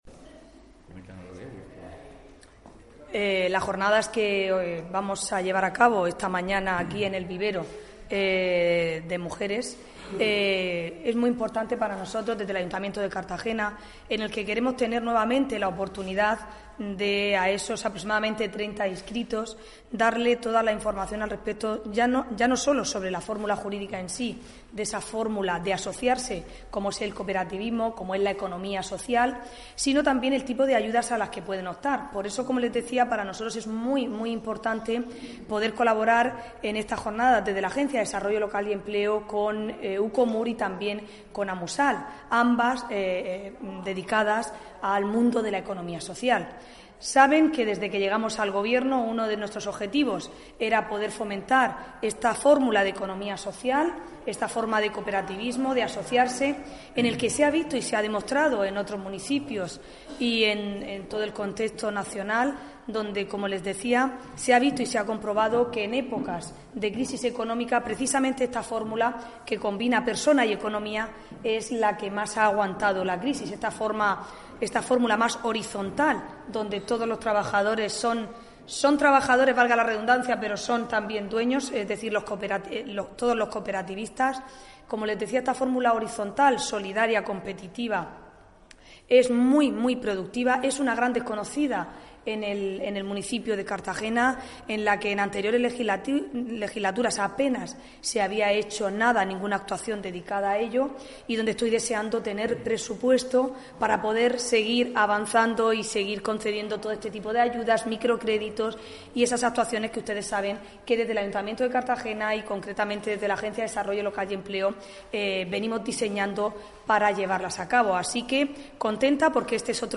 Audio: Inaugura la alcaldesa, Ana Bel�n Castej�n, las Jornadas sobre Econom�a Social en el Vivero de Empresas (MP3 - 3,25 MB)